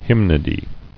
[hym·no·dy]